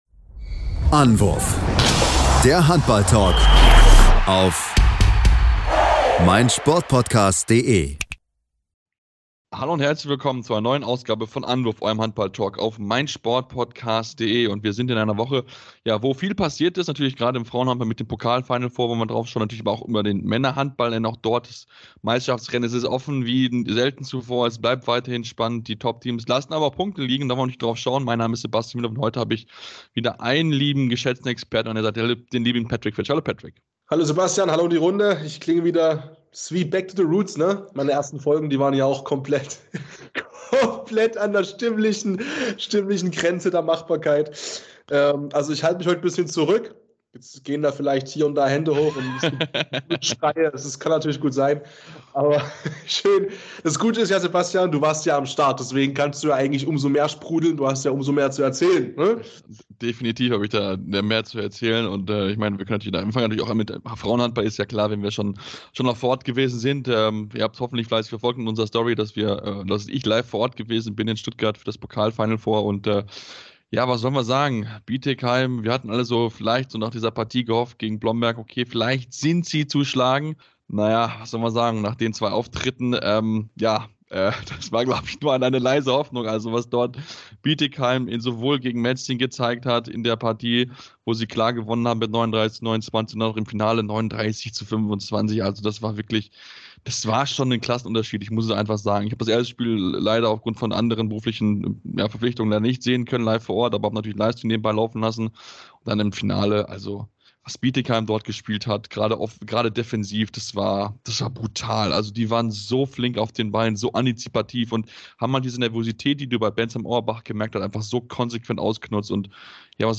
Neben zahlreichen Interviews gibt es aber auch bei den Männern viel zu besprechen. Bietigheim feiert den historischen Pokal-Triumph, denn sie recken zum dritten Mal in Folge den DHB-Pokal in die Höhe.